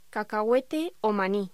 Locución: Cacahuete o maní